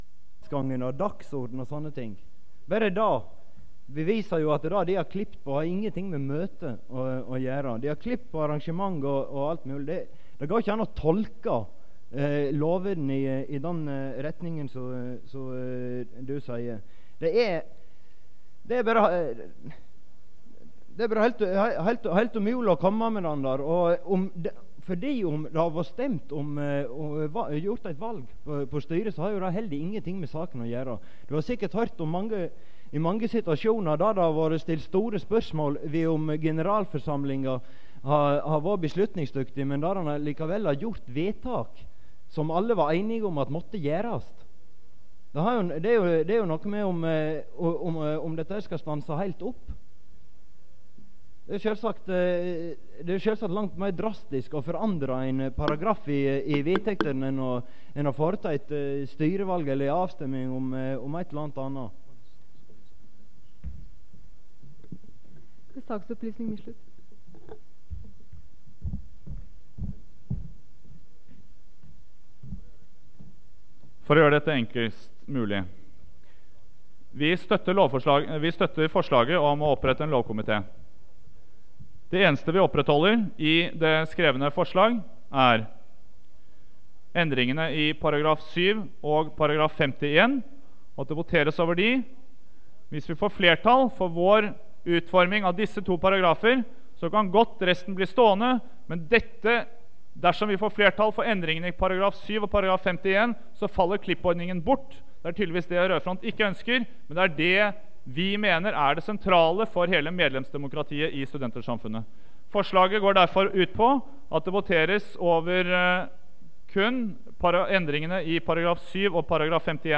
Det Norske Studentersamfund, Generalforsamling, 16.11.1986 (fil 3-4:4)
Generalforsamling (kassett)